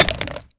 grenade_hit_07.WAV